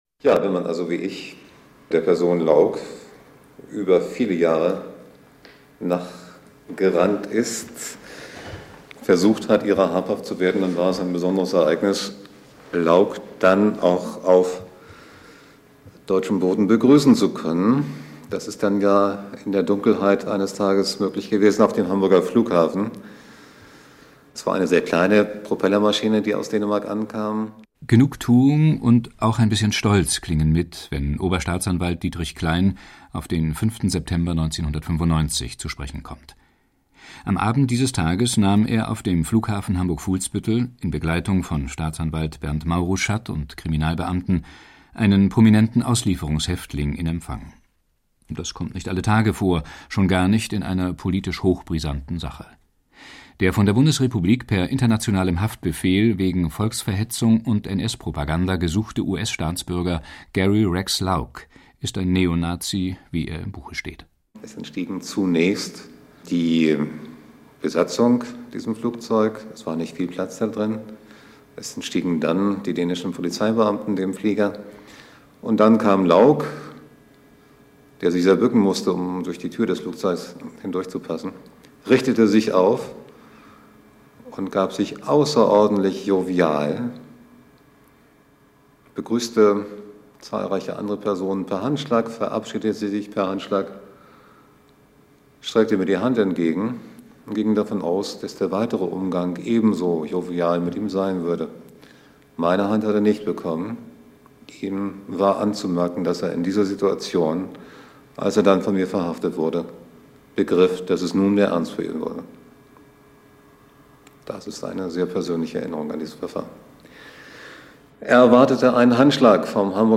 Feature Südwestfunk/ Süddeutscher Rundfunk 2, 5.